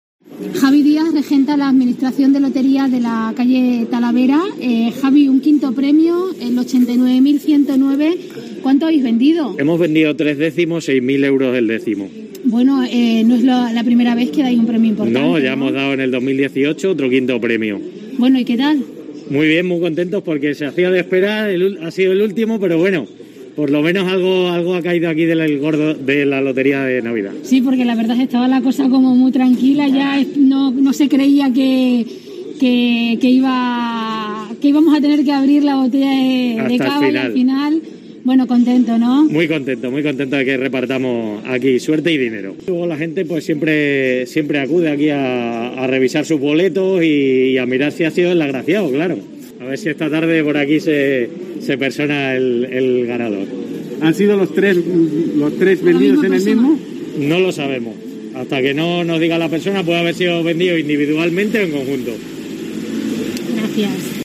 hablaba esta mañana para los micrófonos de COPE Plasencia, a la vez que brindaba con clientes y vecinos con alegría por lo ocurrido.